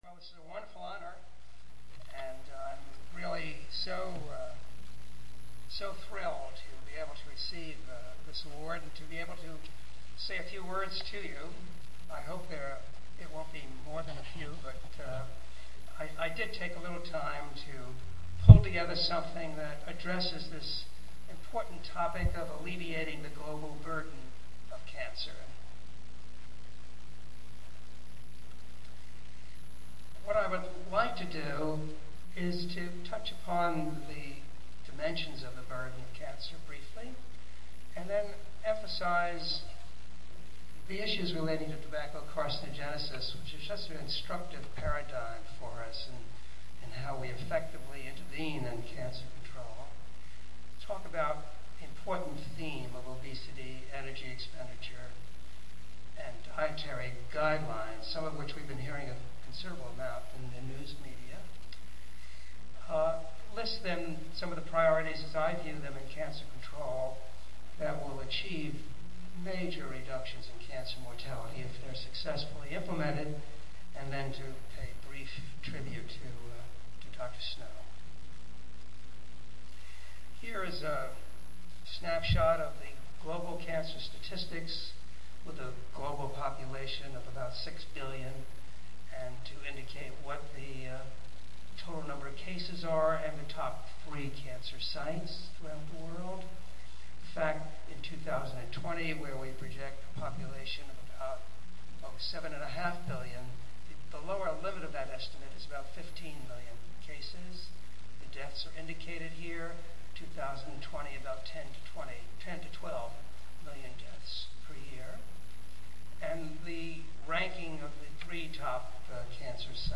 Epidemiology Section Awards Ceremony
Wade Hampton Frost Lecture: A Personal Journey into New and Emerging Infectious Diseases